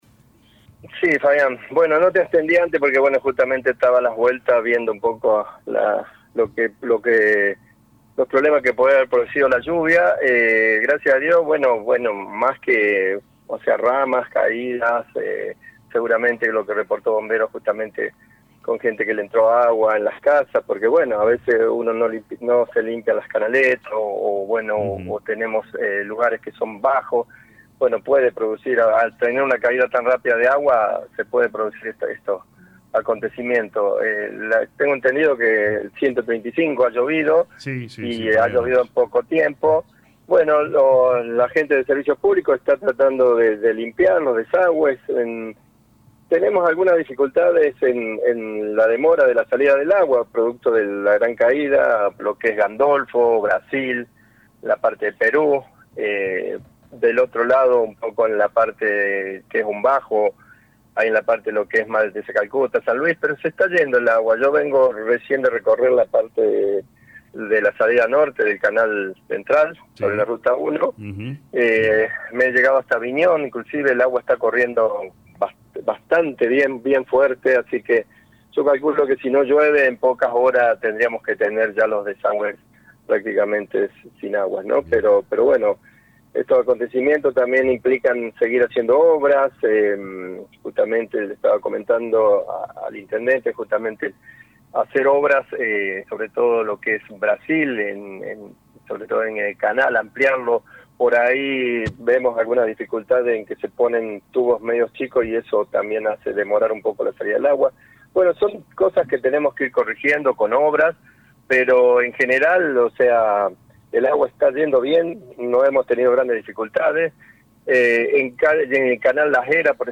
En el comienzo de la jornada, LA RADIO 102.9 FM dialogó con el secretario de Servicios Públicos y Ambiente Walter Olivera luego de la intensa lluvia y fuerte tormenta que se desató en horas de la madrugada del lunes.